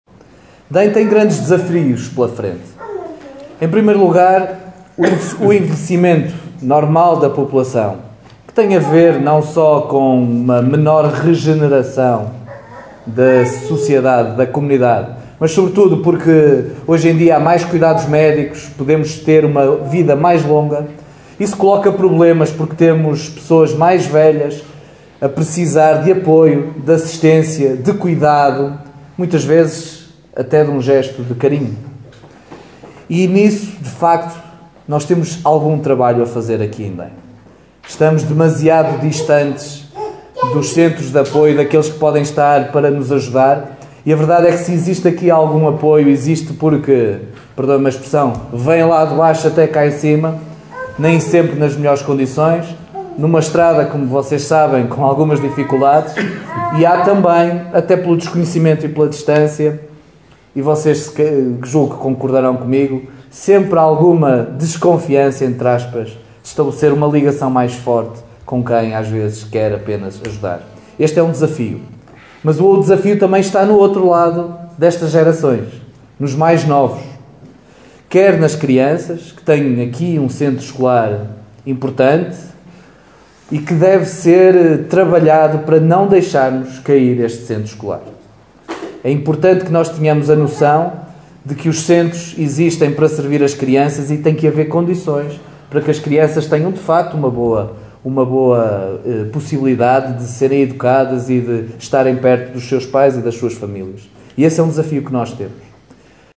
Esta primeira reunião descentralizada da Câmara de Caminha foi encerrada pelo presidente da Câmara. Miguel Alves sublinhou os desafios que a freguesia de Dem vai enfrentar nos próximos tempos.